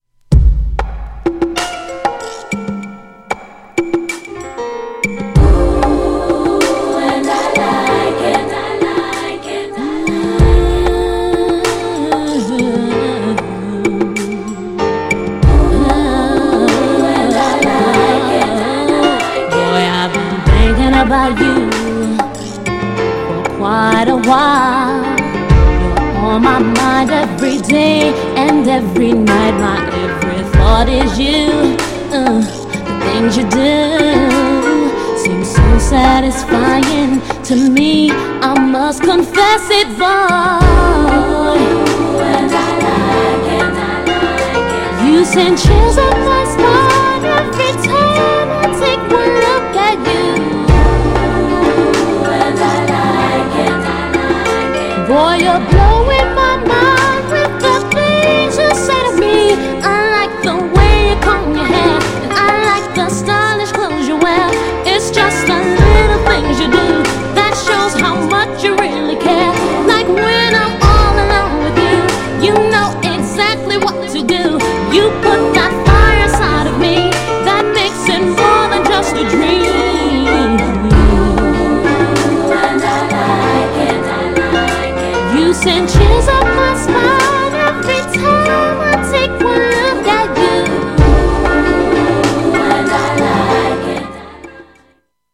GENRE R&B
BPM 96〜100BPM
サビでアガル # メロディアスR&B # 女性コーラスR&B